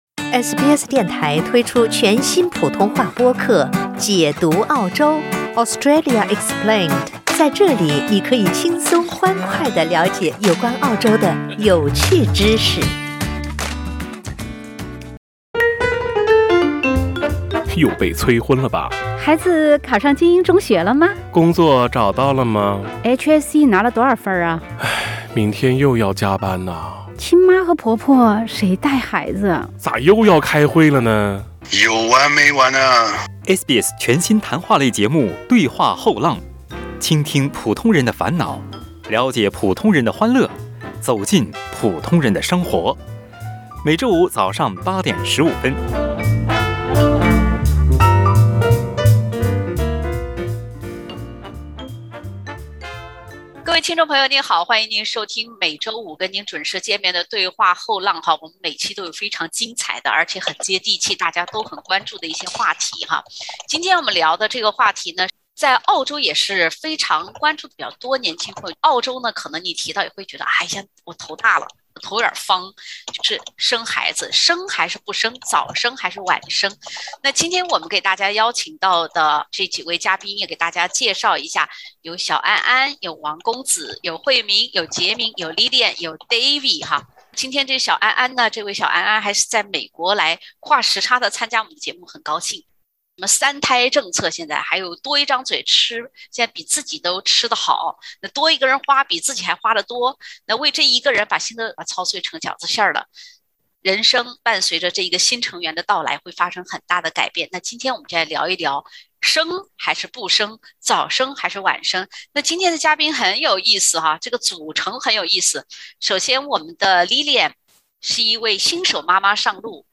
今天的嘉宾中，有早婚早育的，有早婚晚育的，有晚婚晚育的，还有曾经的“白丁。
欢迎收听澳大利亚最亲民的中文聊天类节目-《对话后浪》。